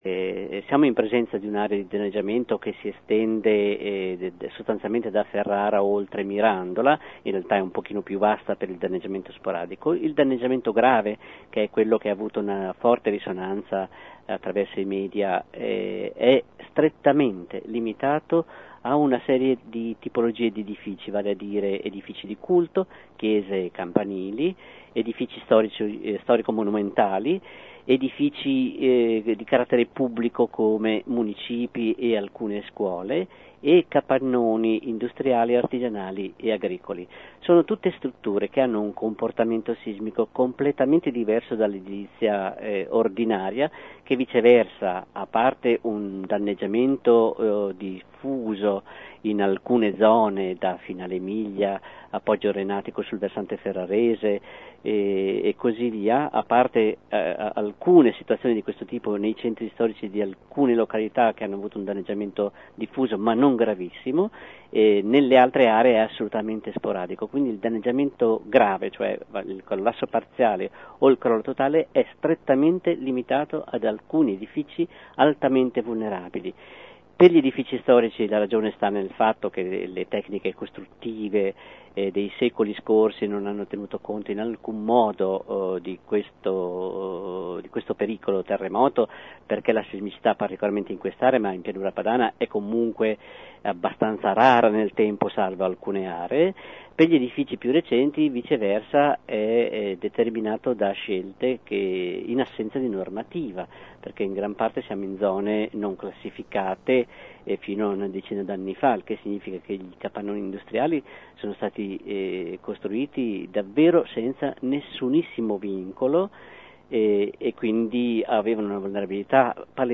Ecco un estratto dell’intervista